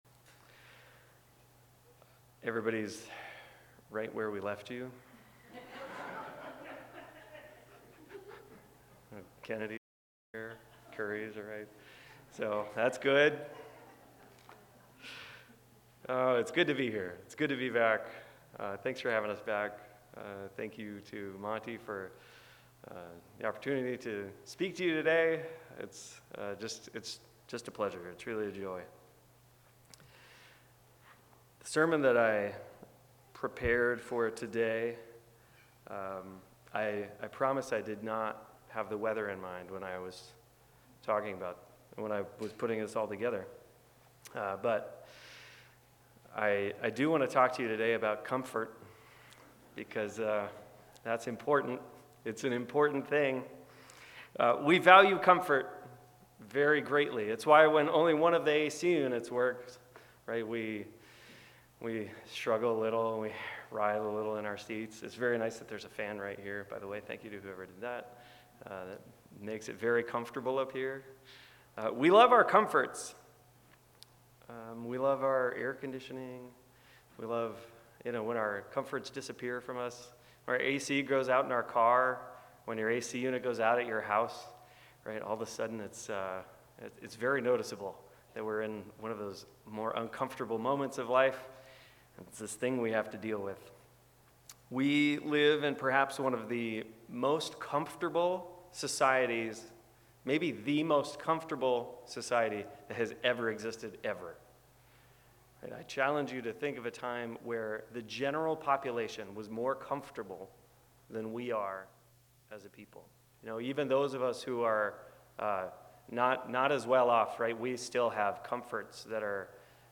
In the opening lines of 2 Corinthians, Paul calls God the "God of all comfort." This is a different comfort, and this sermon differentiates between the underlying ideas.